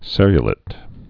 (sĕryə-lĭt, -lāt, sĕrə-) also ser·ru·lat·ed (-lātĭd)